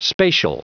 Prononciation du mot spatial en anglais (fichier audio)